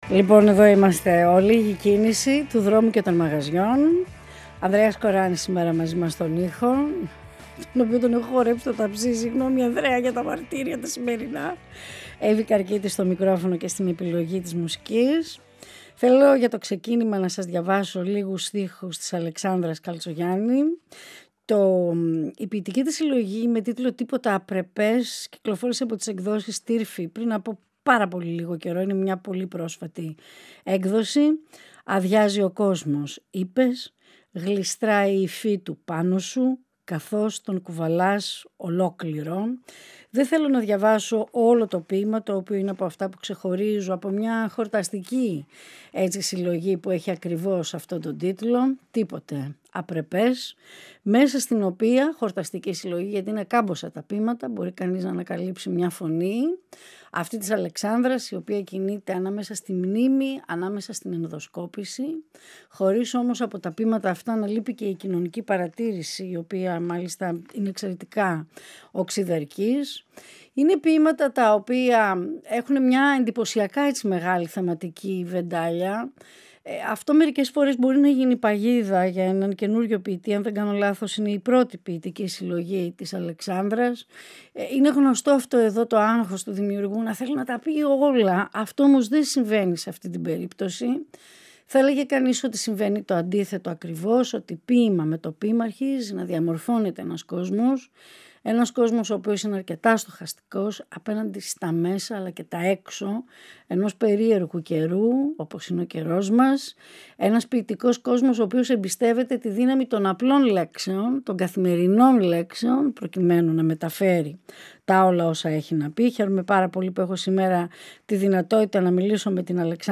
Ολιγη Κινηση του Δρομου και των Μαγαζιων Συνεντεύξεις